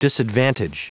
Prononciation du mot disadvantage en anglais (fichier audio)
Prononciation du mot : disadvantage